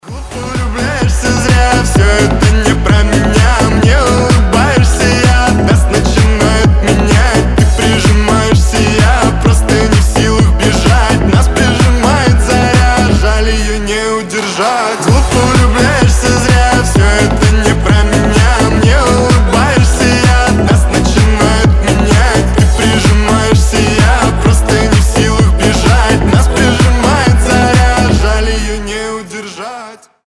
• Качество: 320, Stereo
мужской голос
заводные